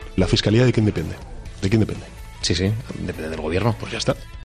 Palabras de Pedro Sánchez.